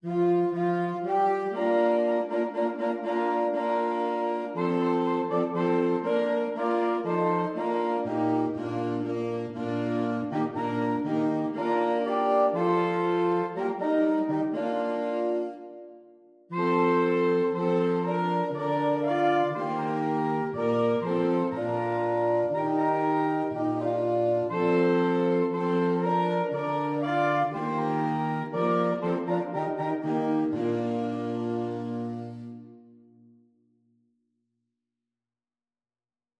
2/4 (View more 2/4 Music)
Allegro = c.120 (View more music marked Allegro)
Saxophone Quartet  (View more Easy Saxophone Quartet Music)